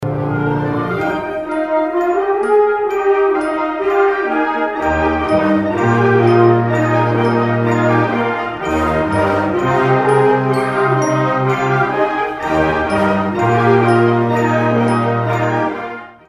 CLICK HERE] is Cinematic Symphony performing what music?